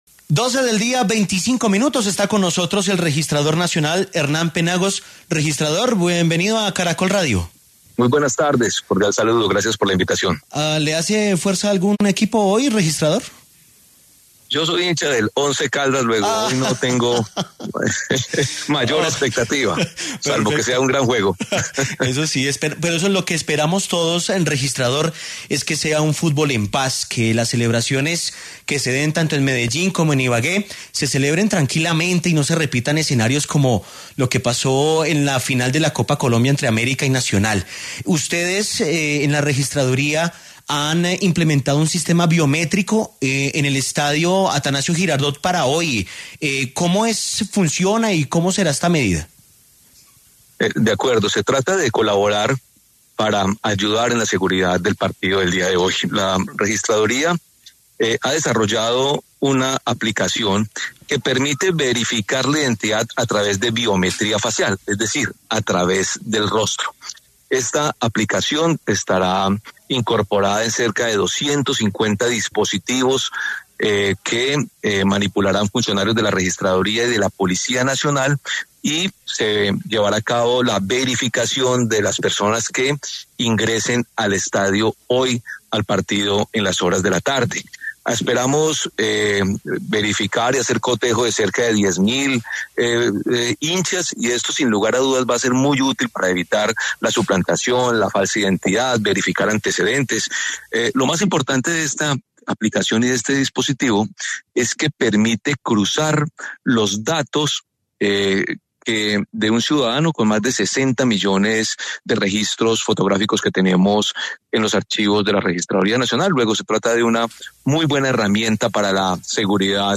Previo al inicio de la final del Fútbol Profesional Colombiano, entre Atlético Nacional y Deportes Tolima, el registrador nacional Hernán Penagos habló en Caracol Radio sobre la posibilidad de seguir implementando las medidas de reconocimiento facial en estos eventos deportivos a nivel nacional, luego de que se anunciara que habrá cerca de 600 para hacer estos seguimientos en tiempo real en el Atanasio Girardot.